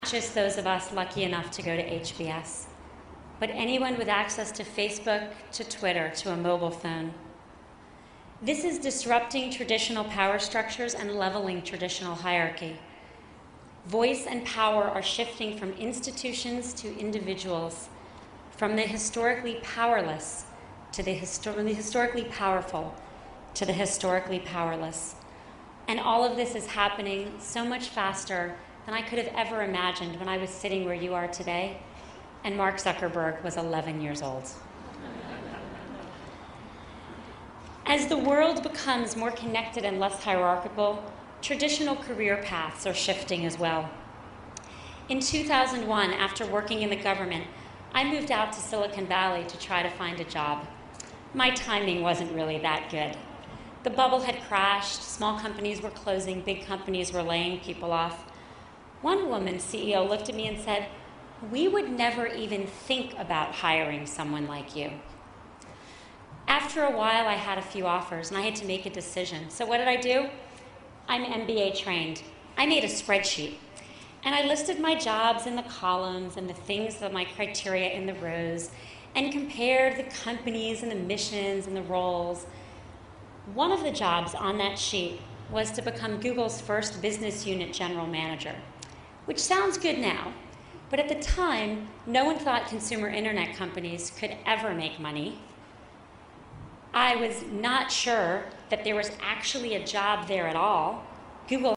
公众人物毕业演讲第178期:桑德伯格2012哈佛商学院(3) 听力文件下载—在线英语听力室